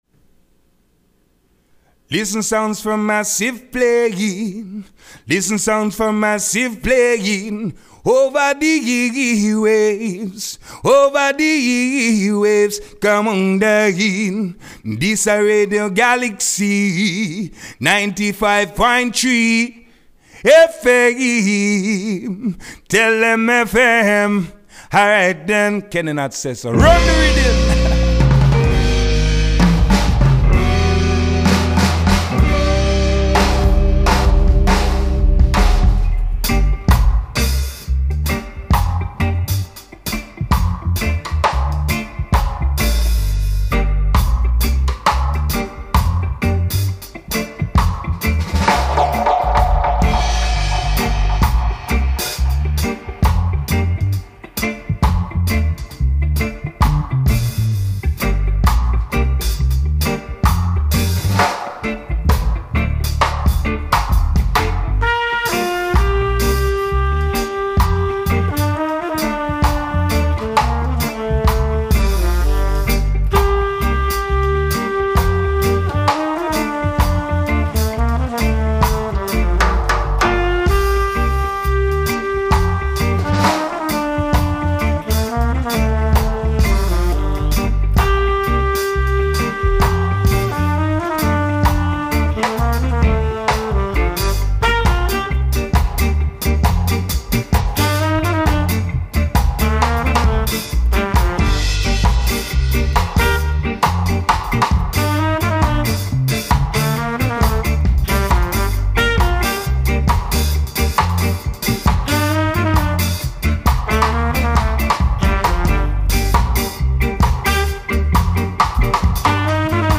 Sound 4 Massive - le REPLAY Retrouvez votre rendez-vous reggaephonique animé par I Bingi Sound et enregistré lundi 17 novembre dans les studios de Galaxie Radio.